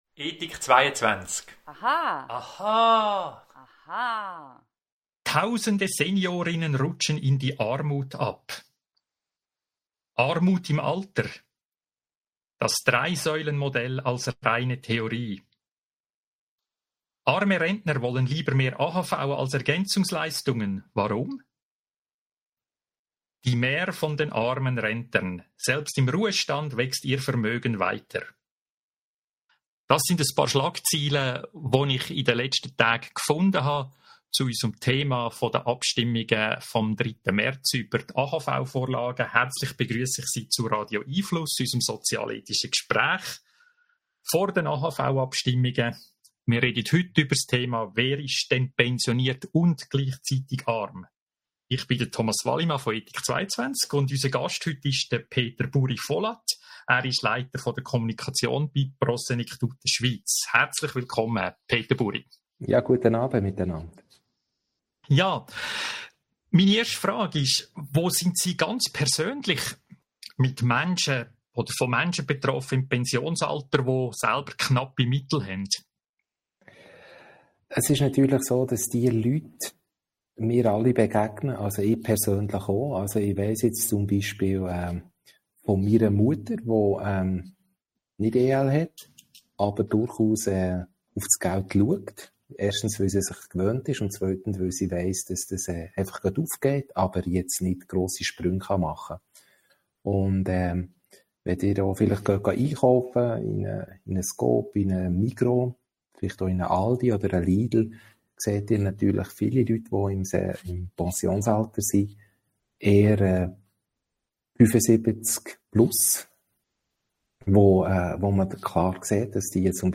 Bleiben Sie über die kommenden Radio🎙einFluss Audio-Gespräche informiert!